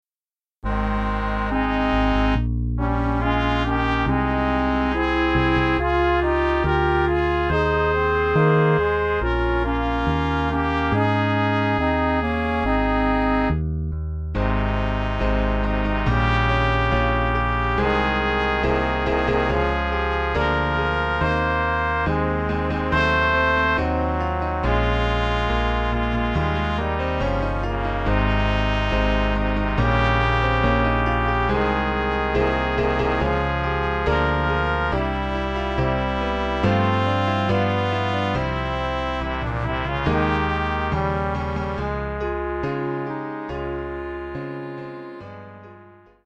Sample from the Backing CD